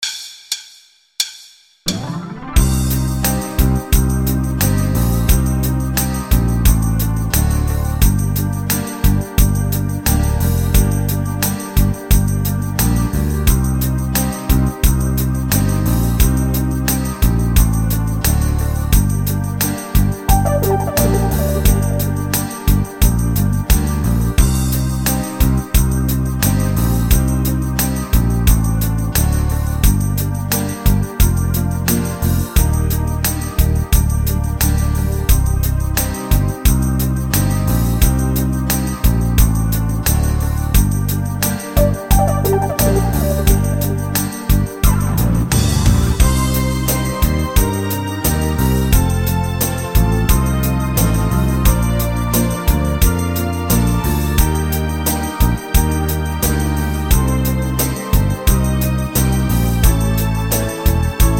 instr.Saxophon